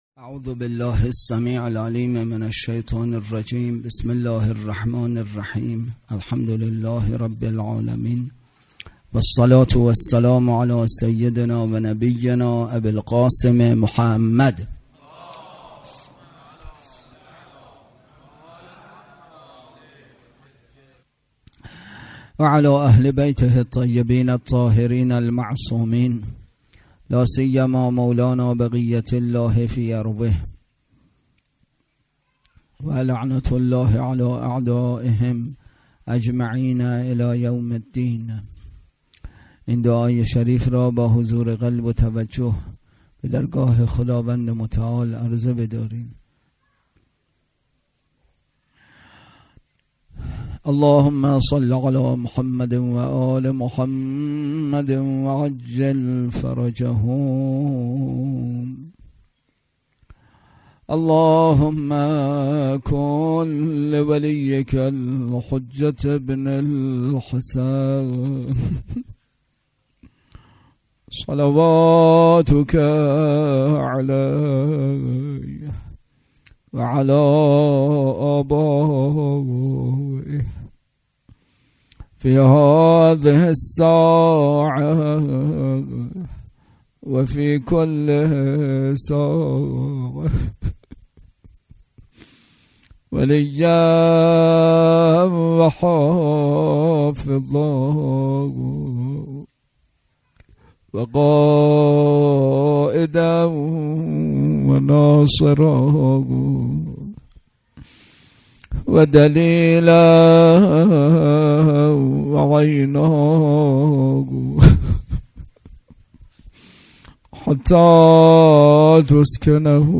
اطلاعات آلبوم سخنرانی
برگزارکننده: مسجد اعظم قلهک